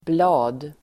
Uttal: [bla:d]